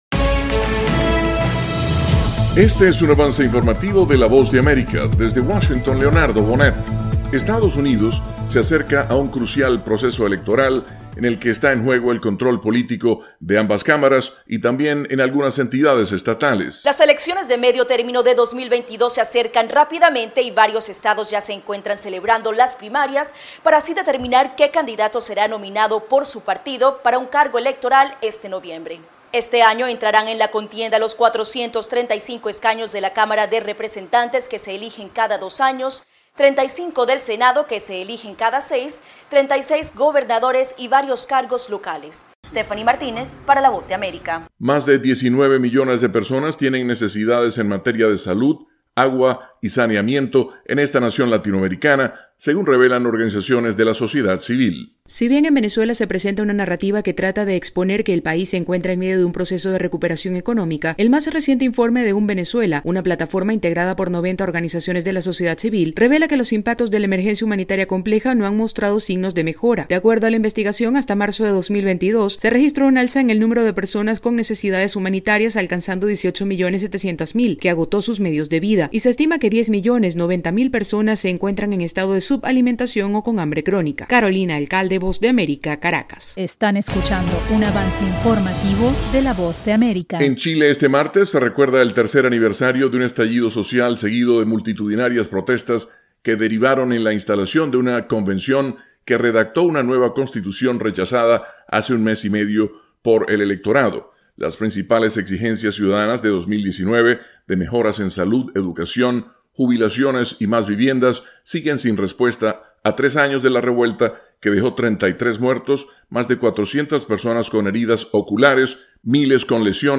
Avance Informativo 4:00 PM